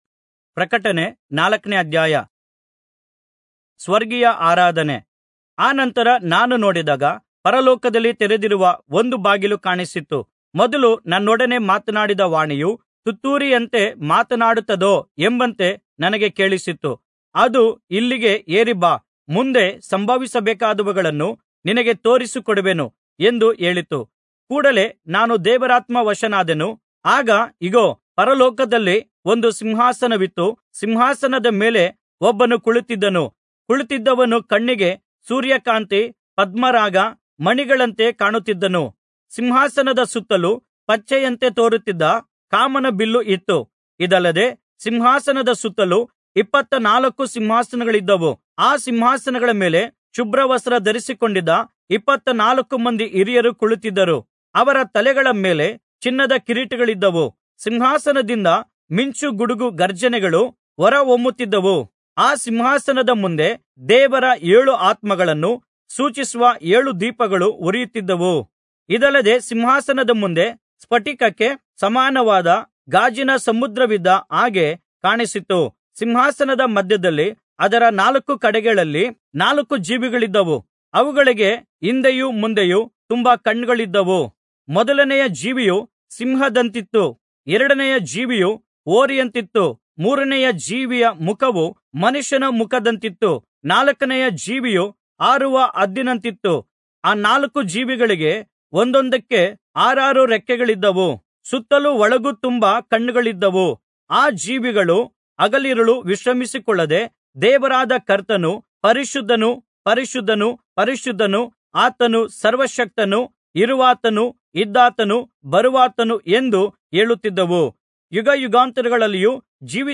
Kannada Audio Bible - Revelation 17 in Irvkn bible version